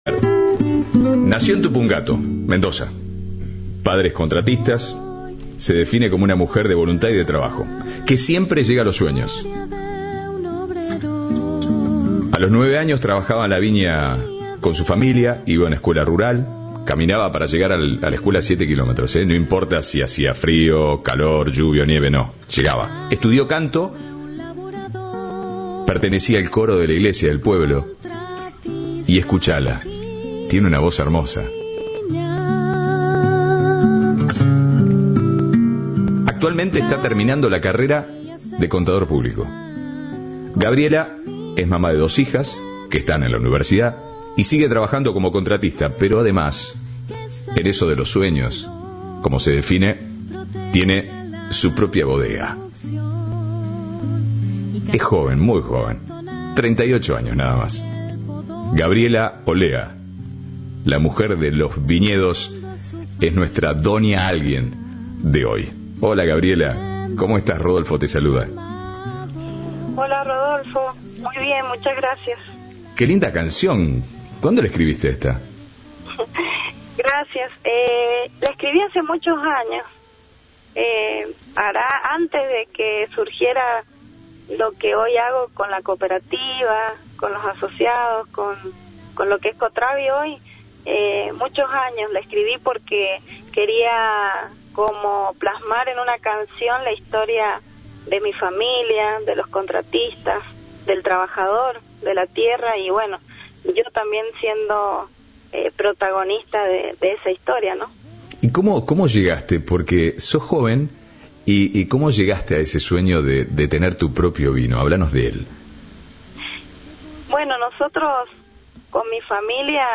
Entrevista de Rodolfo Barili